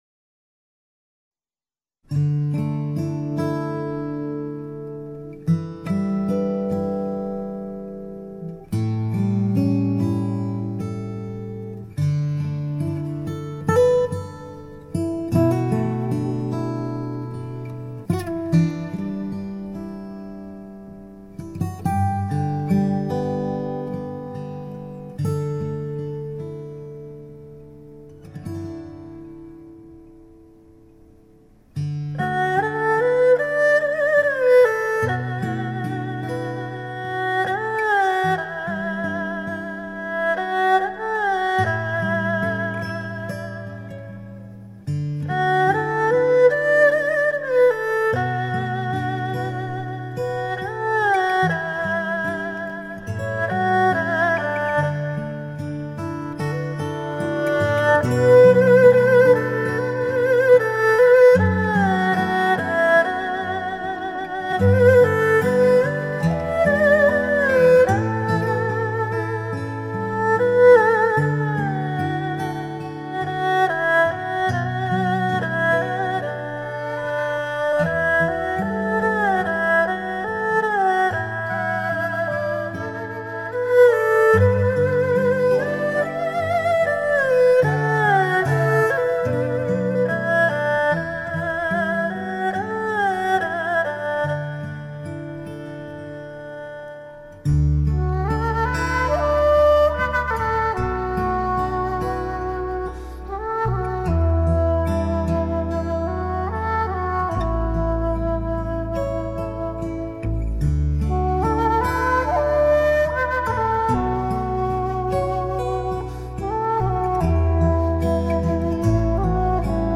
风格甚为古朴、儒雅
二胡音像“形态”丰满厚实，音色甜润醇和而且胆味浓郁，质感之真实犹如亲临录音现场。